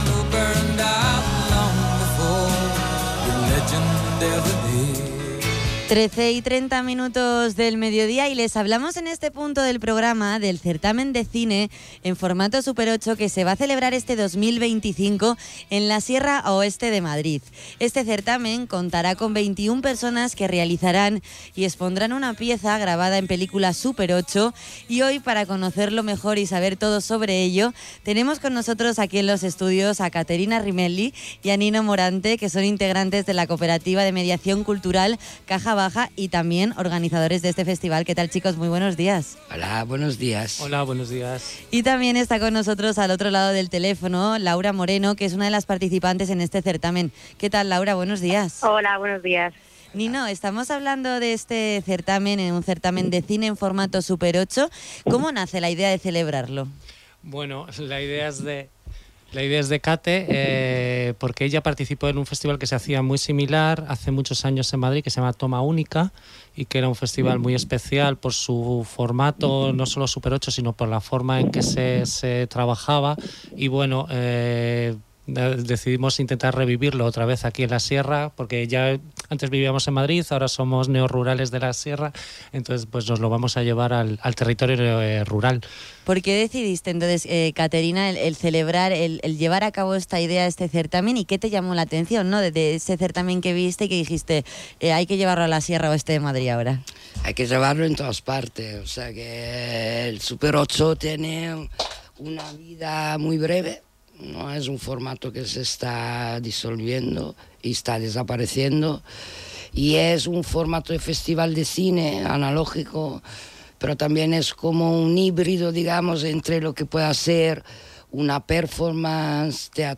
VER VIDEO CAMPAÑA El Certamen 16/2 en «Hoy por hoy» de SER Madrid Sierra 16/2 en radio Sierra Oeste de Madrid NOS HAN DADO VOZ EN SER SIERRA OESTE DE MADRID, DONDE HABLAMOS DE LA PRIMERA EDICIÓN QUE SE CELEBRARÁ EL 13 DE DICIEMBRE DE 2025 EN EL TEATRO LISADERO DE ROBLEDO DE CHAVELA. PUEDES ESCUCHAR LA ENTREVISTA COMPLETA JUSTO AQUÍ ABAJO.
Entrevista-SER-OESTE.mp3